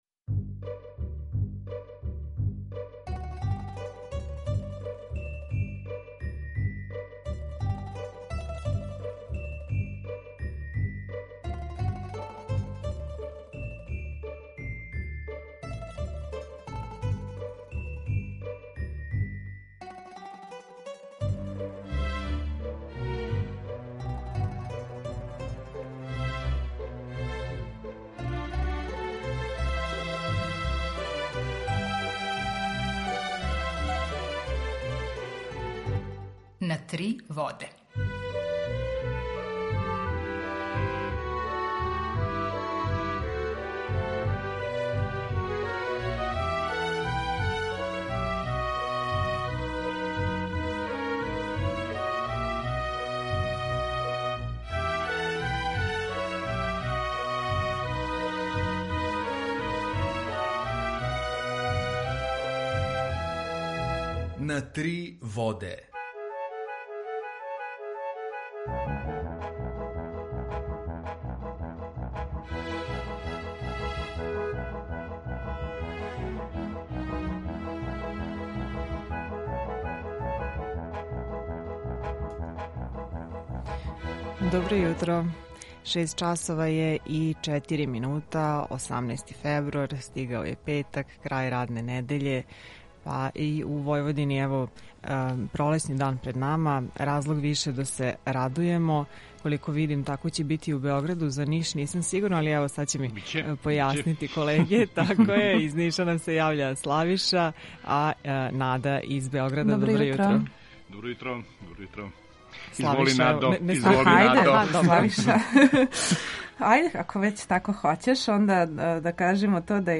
Емисију реализујемо заједно са студијом Радија Републике Српске у Бањалуци и Радијом Нови Сад
Јутарњи програм из три студија
У два сата, ту је и добра музика, другачија у односу на остале радио-станице.